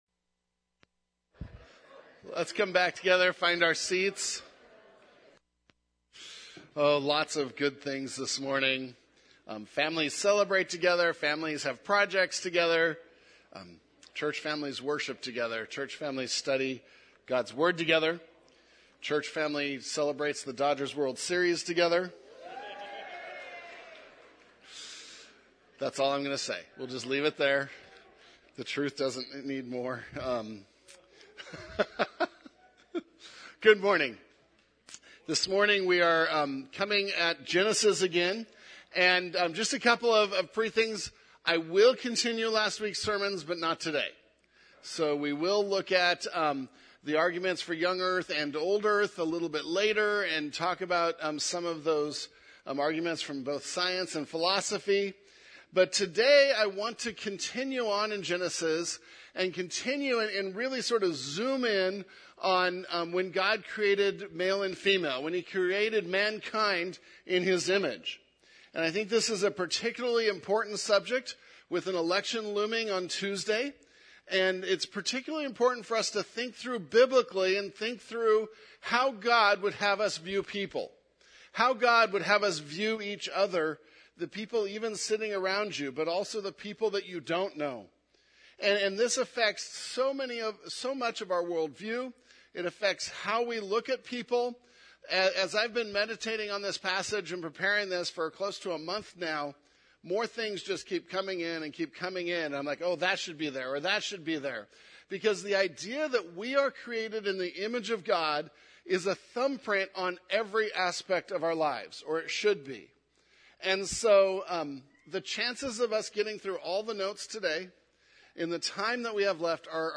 Nov 03, 2024 In God’s Image (Genesis 1:26-28) MP3 SUBSCRIBE on iTunes(Podcast) Notes Discussion Sermons in this Series Loading Discusson...